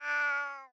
豹猫空闲时随机播这些音效
Minecraft_ocelot_idle4.mp3